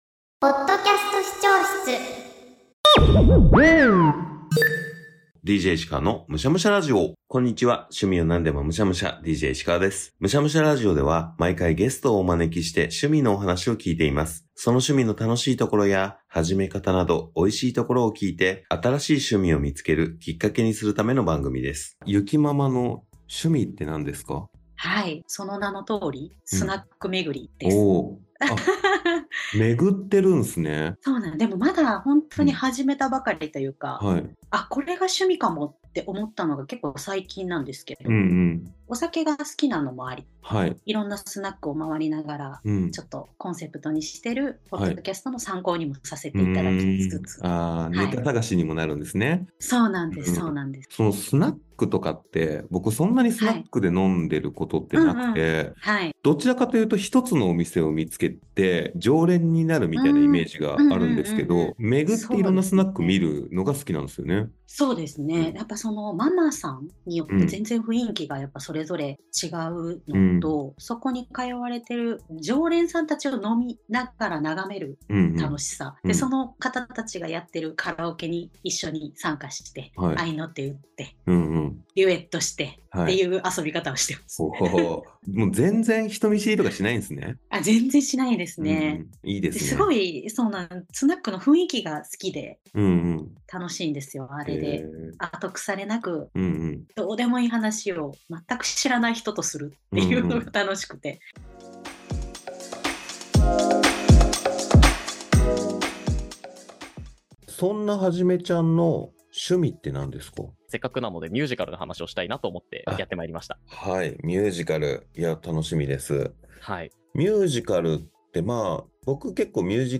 毎回さまざまな方に趣味の話を聞いて、美味しいところをいただきます！